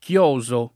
chiosare v.; chioso [ k L0@ o ]